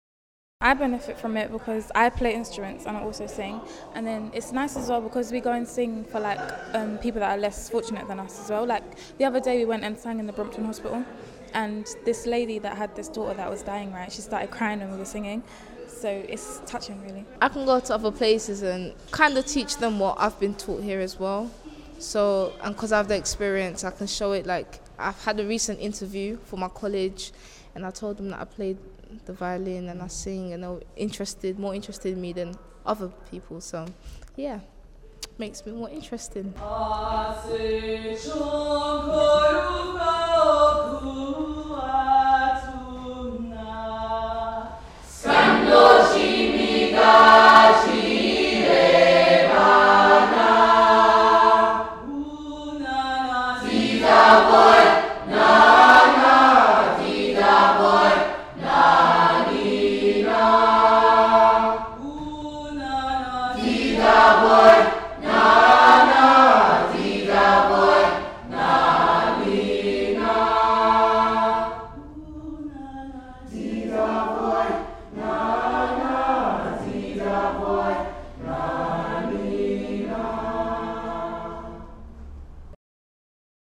Young members talk about the benefits of the choir